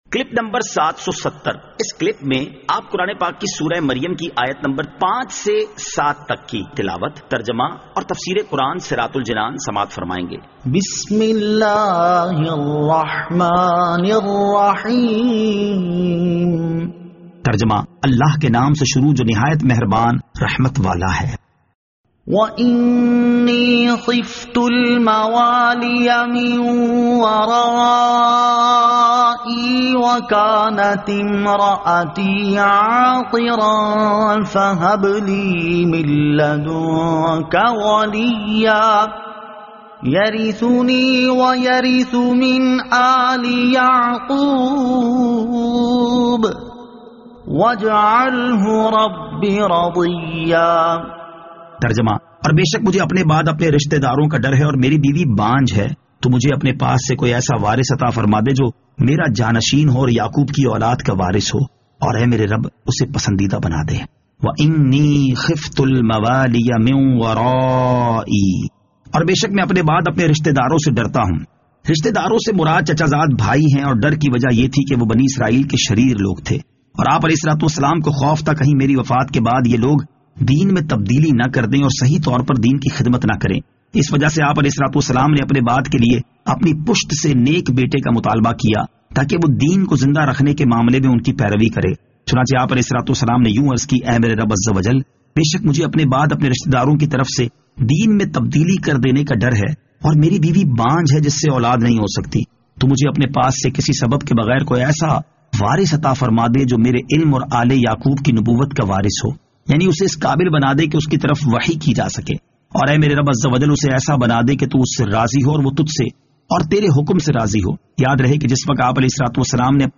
Surah Maryam Ayat 05 To 07 Tilawat , Tarjama , Tafseer